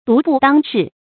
独步当世 dú bù dāng shì
独步当世发音